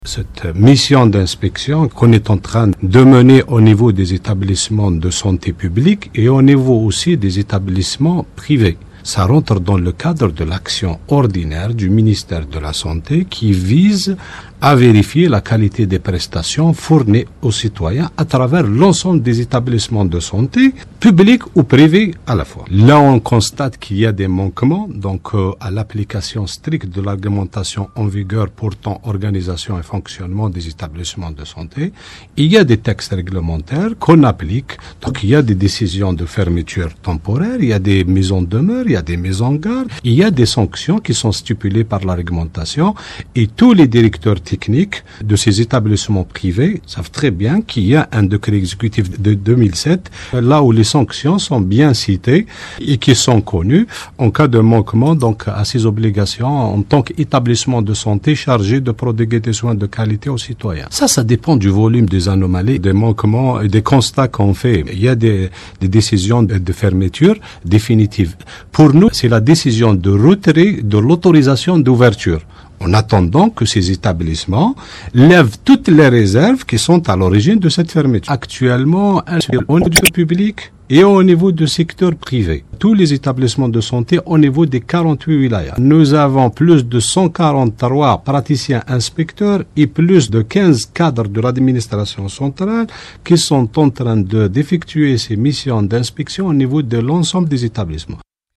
Omar Bedjouane, inspecteur général au ministère de la santé, à la radio Chaine 3